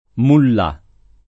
mullah → mullā